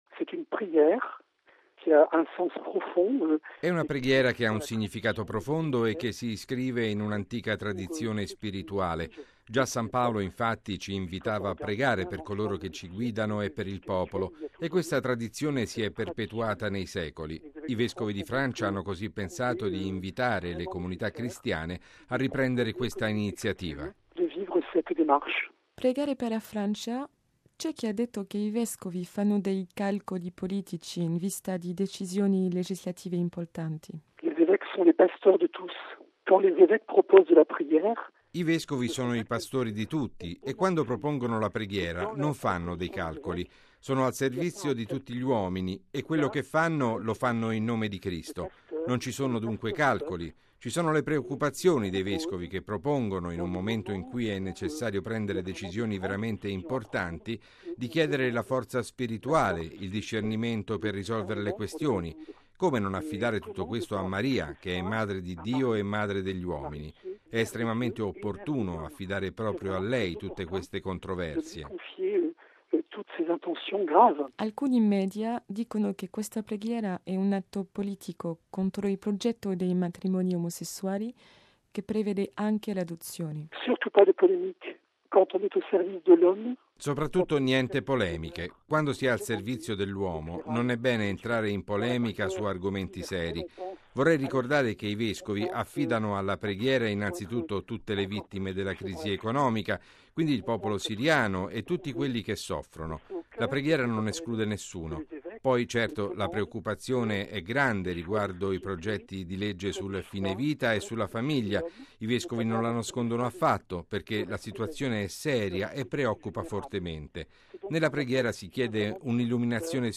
Radiogiornale del 15/08/2012 - Radio Vaticana